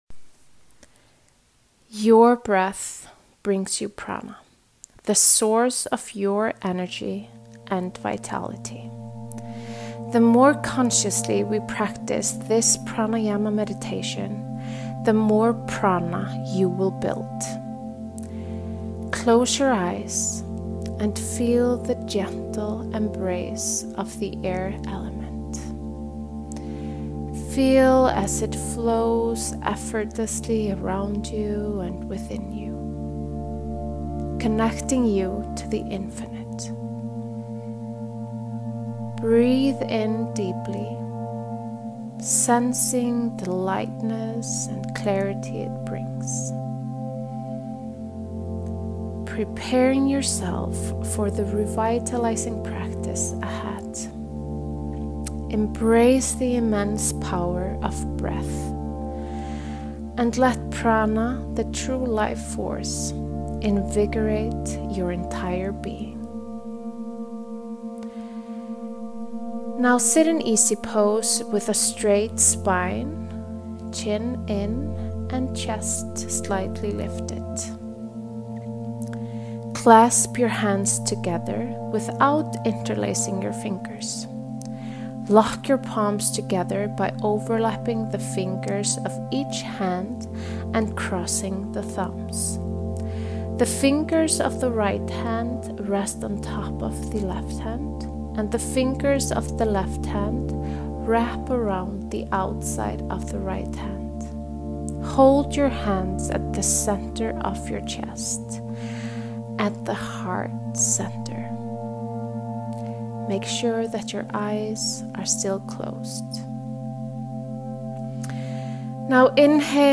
airmeditation.mp3